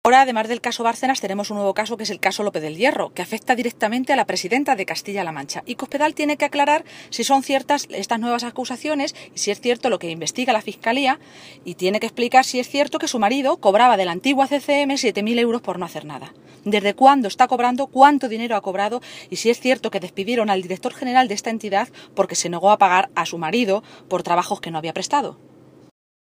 Cristina Maestre, Vicesecretaria y portavoz del PSOE de Castilla-La Mancha
Cristina Maestre se pronunciaba de esta manera en una comparecencia ante los medios de comunicación, esta mañana, en Toledo, convocada tras las informaciones publicadas por el diario El Mundo en su edición de hoy.
Cortes de audio de la rueda de prensa